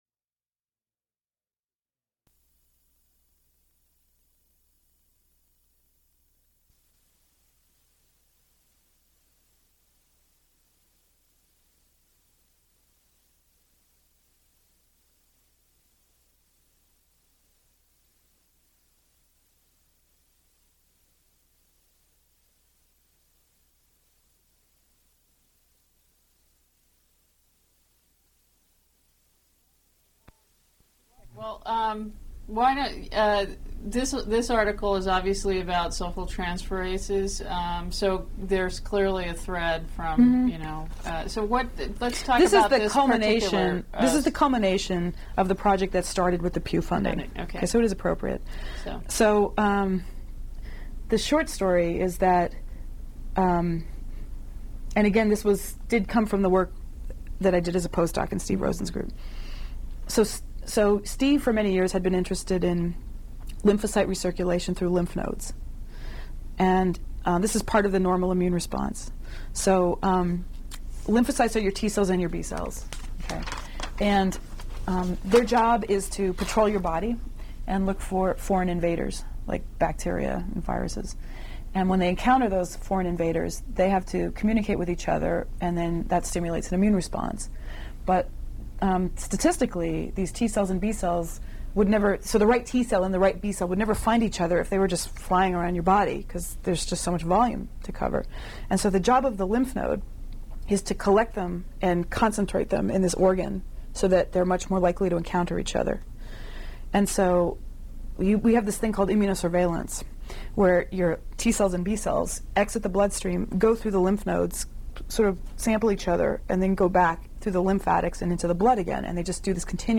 Complete transcript of interview